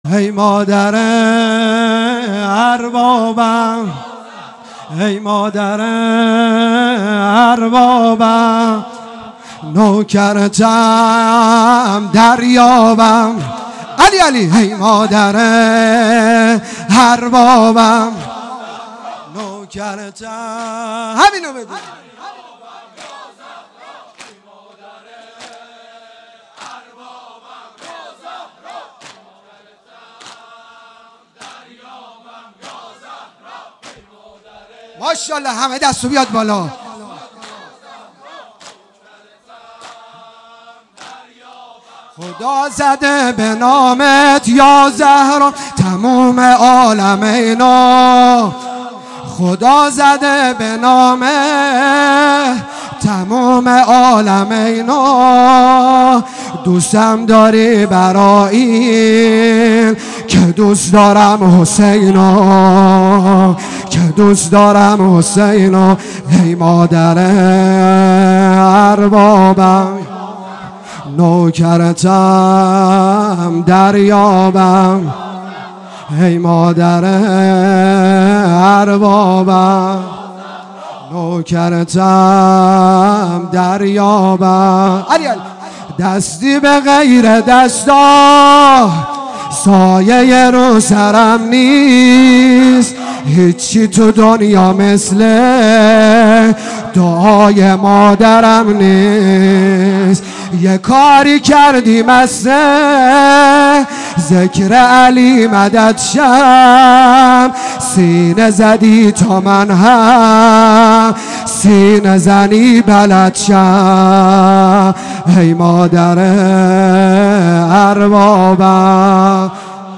مادرانه|دم هیئت مادر بی نشان|ای مادر اربابم نوکرتم دریابم
هیئت مادر بی نشان